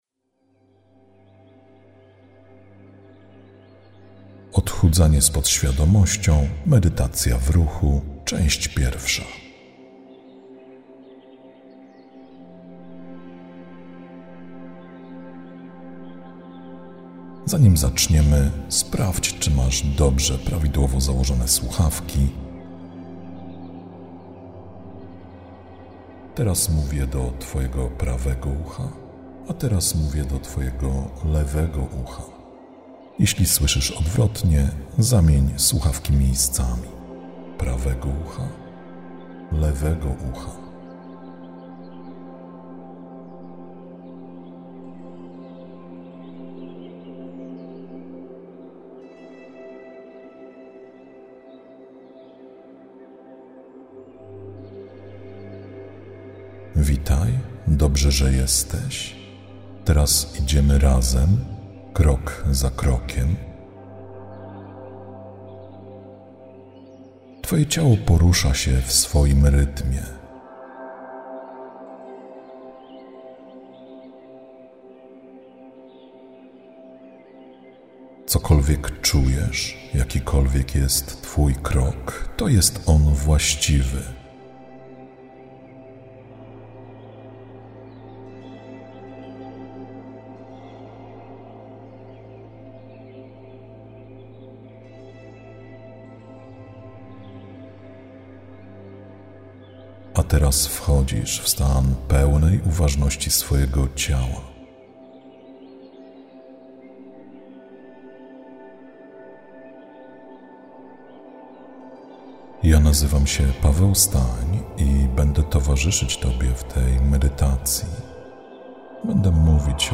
Czas trwania: 1:09:45 Jakość: Hi-Fi 320 kbps Rozmiar: 117 MB (1 plik ZIP) Zawiera lektora: Tak Zalecane słuchawki: TAK Rok wydania: 2024 Instrukcja PDF: Tak